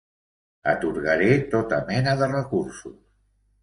Read more Noun Verb menar to lead; to guide; to direct Read more Frequency A1 Pronounced as (IPA) [ˈmɛ.nə] Etymology Inherited from Vulgar Latin *mīna, *mēna, from Gaulish *meina (“mineral, ore”).